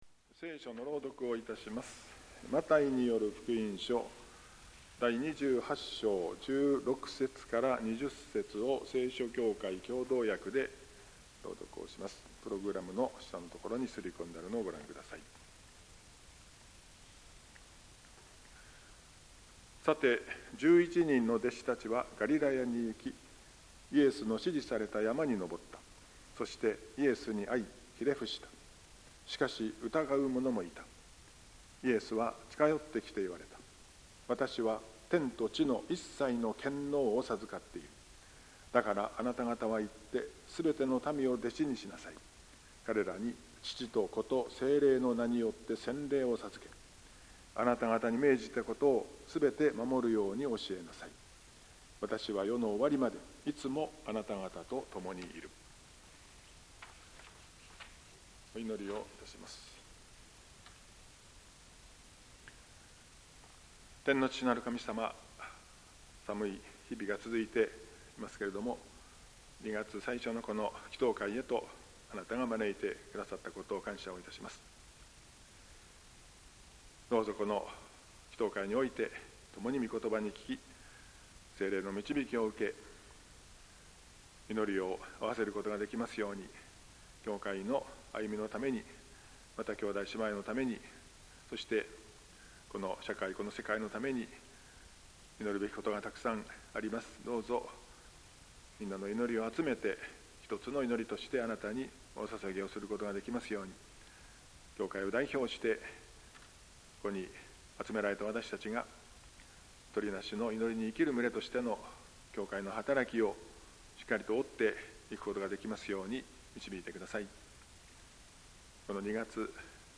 2026年2月奨励「私は天と地の一切の権能を授かっている。」